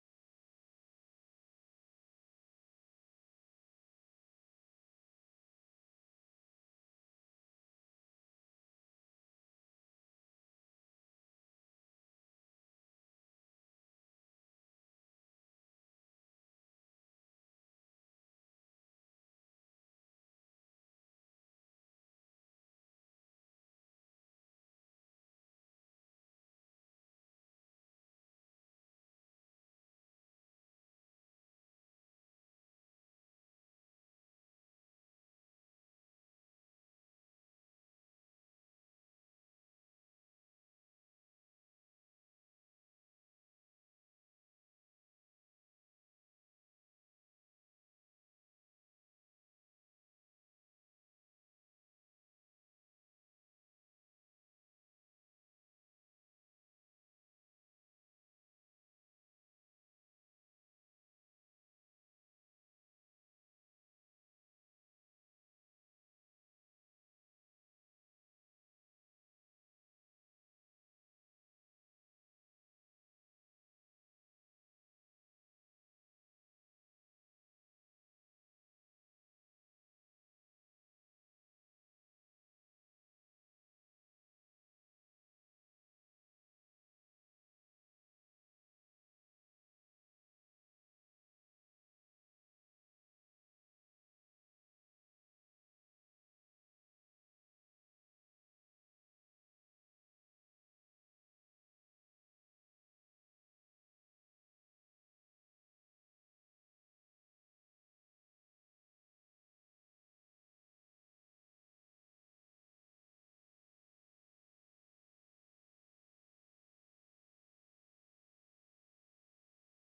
Responsibility-Calling-Part-2-Sermon-Audio-CD.mp3